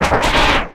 Cri d'Arcko dans Pokémon X et Y.